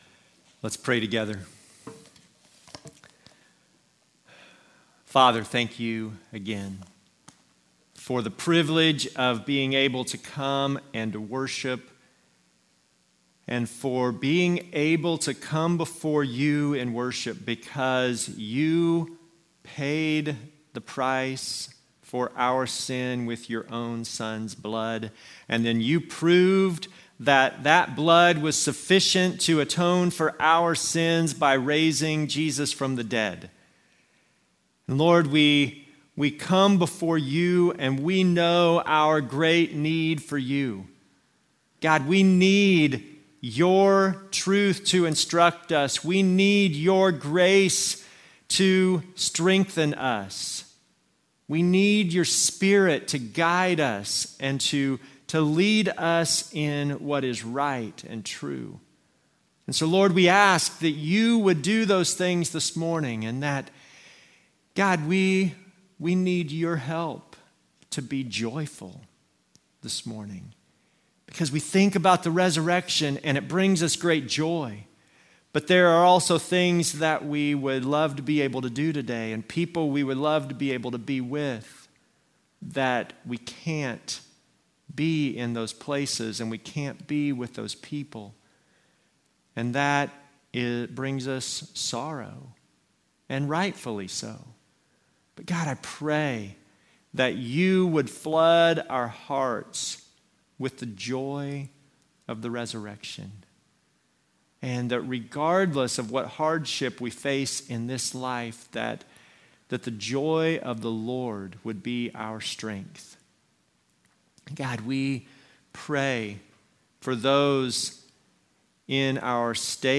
Service Type: Normal service